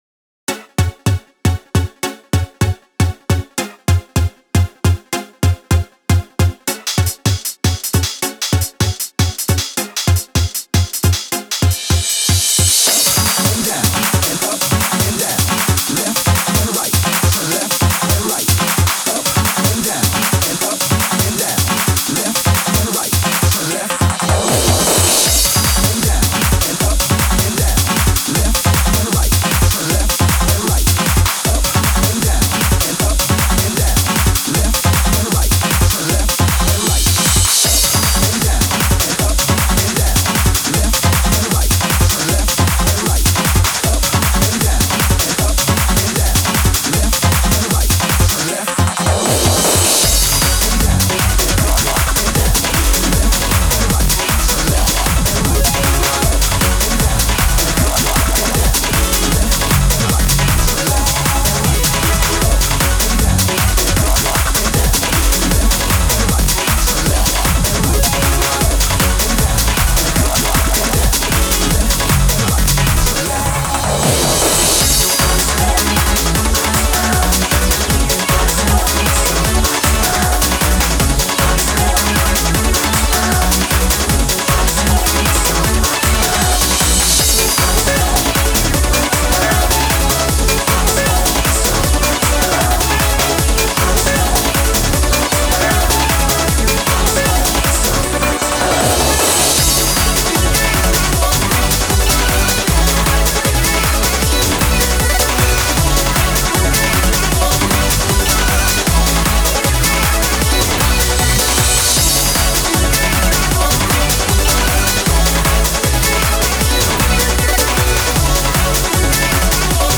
Techno (DUB's Edit)
今までのメロディ重視とは異なり、ダブステの魅力も兼ねた王道テクノとなってます。